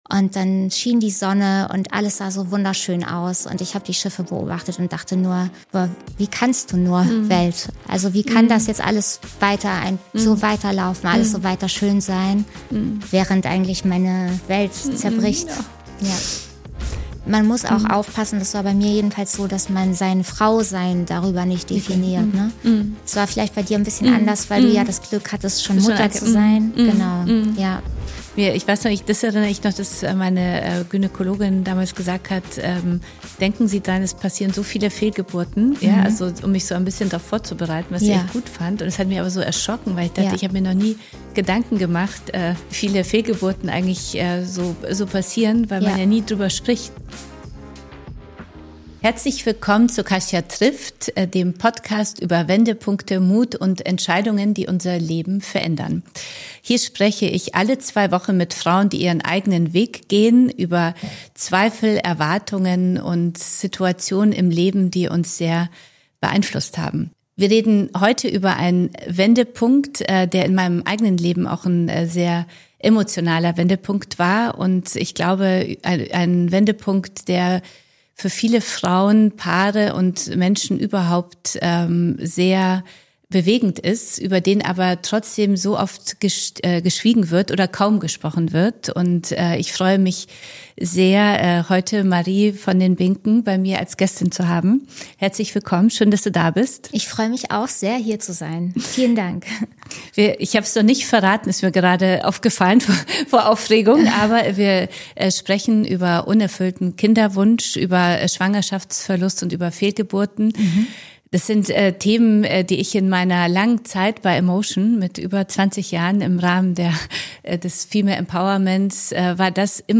Ein Gespräch über Hoffnung und Enttäuschung. Über gesellschaftlichen Druck und persönliche Trauer.